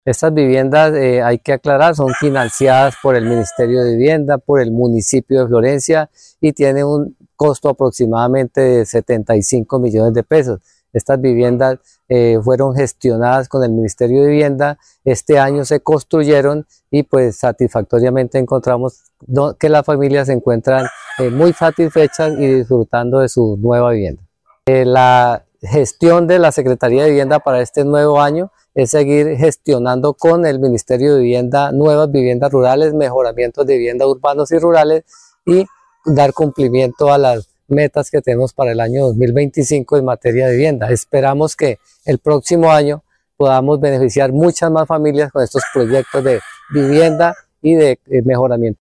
El secretario de Vivienda, Silvio Lara Barrero, dijo que en la vereda Nazareth se verificó la construcción de un número importante de estas viviendas.